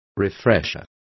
Complete with pronunciation of the translation of refresher.